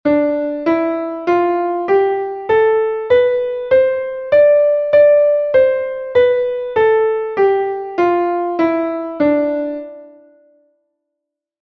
Dórico
dorico.mp3